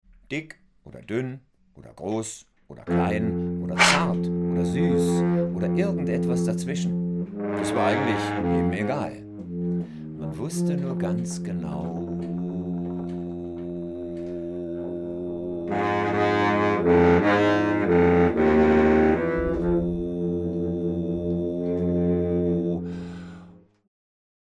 trombone
baritone saxophone
voice
field recordings at SuperGau-Festival Lungau (May 2023)